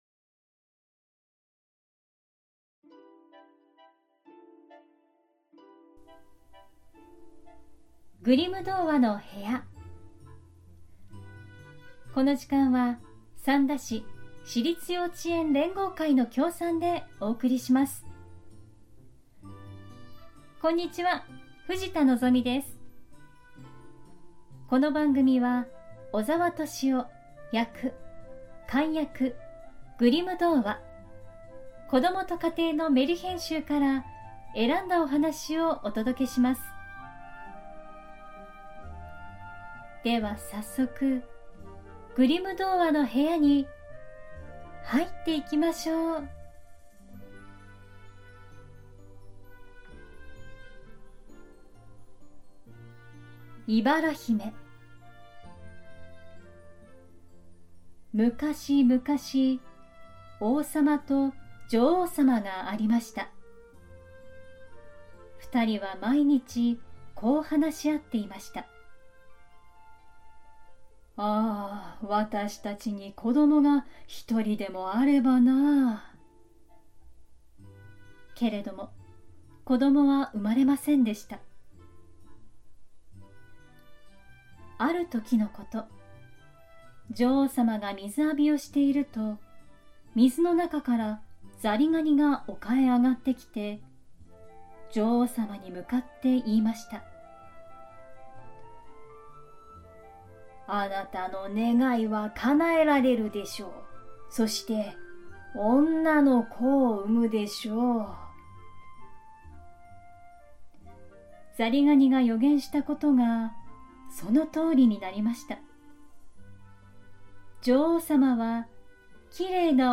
グリム兄弟によって集められたメルヒェン（昔話）を、翻訳そのままに読み聞かせします📖 2025年ラストにお届けするのは『いばら姫』。